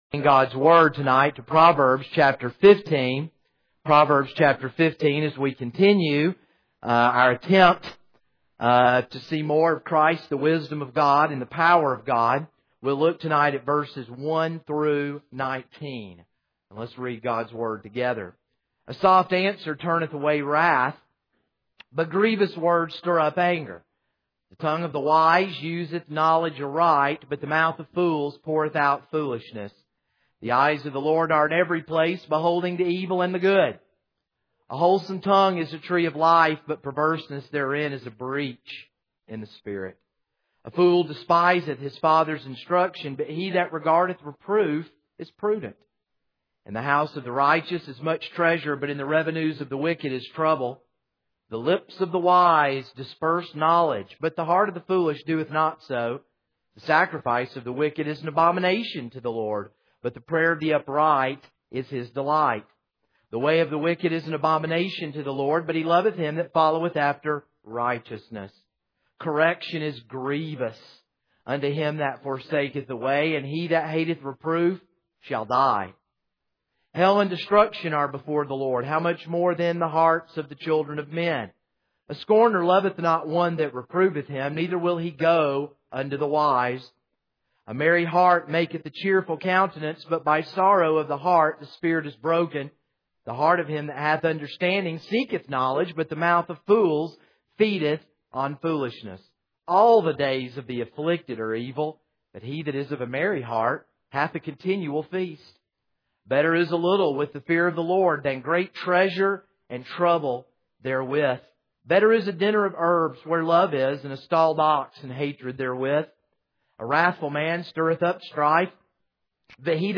This is a sermon on Proverbs 15:1-19.